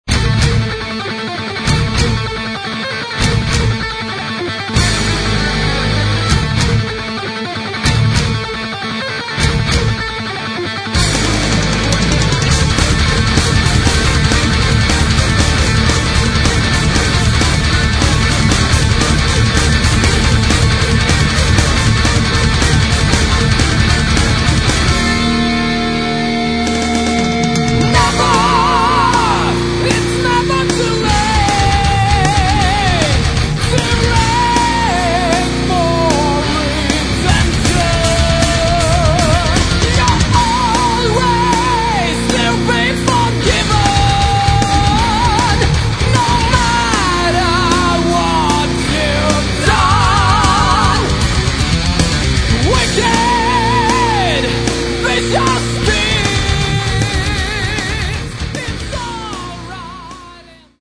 Metal
гитара
барабаны
вокал
бас